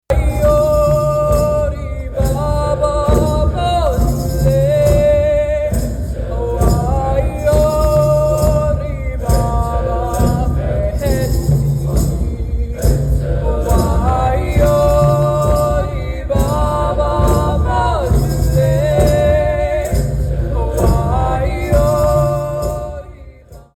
The two-day celebration ended Thursday with a better-than-hourlong performance from students at Emporia High School and Emporia Middle School.